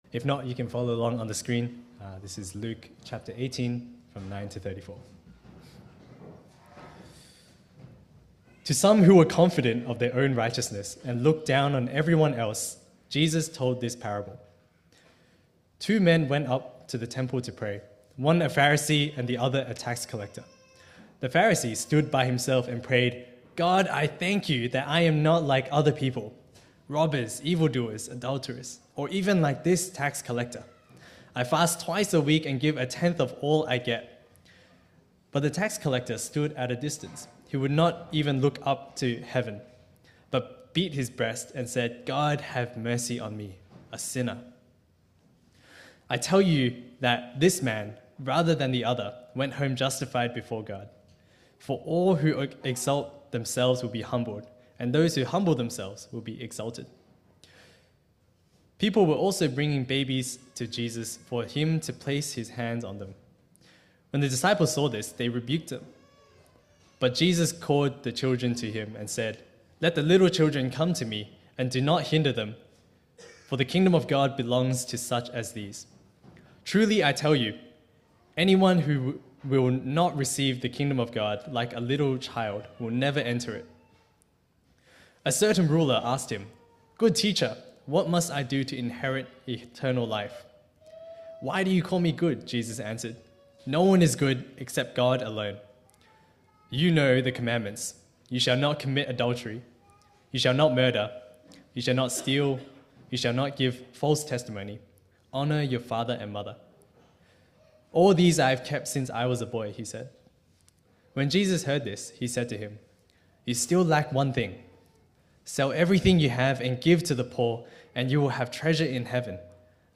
Passage: Luke 18:9-34 Service Type: 10:45 English